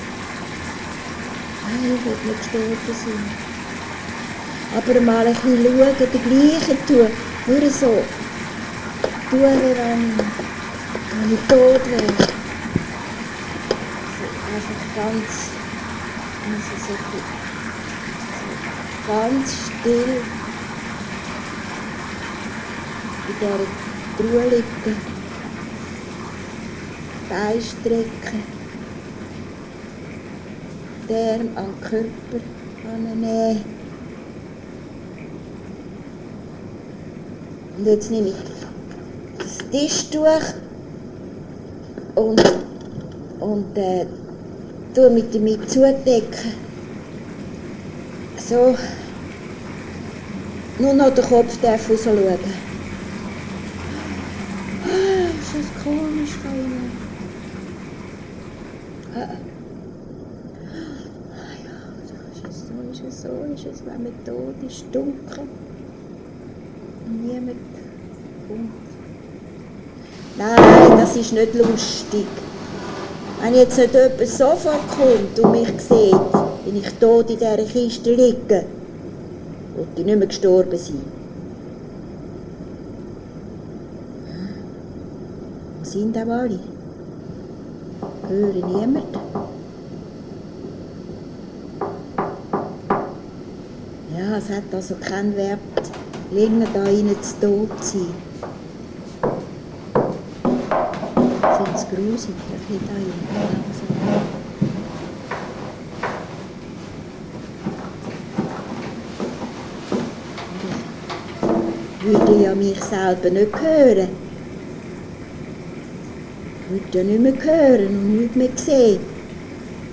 Es waren installative Eingriffe im Spannungsfeld zwischen dem An- und Abwesenden, mit Bild, Klang, Rauch, Schatten Wort und Gesang mit:
Audio-Installation
10Min, Loop
Kindheitserinnerungen einer alten Frau ertönen aus ihrem früheren Lieblingsversteck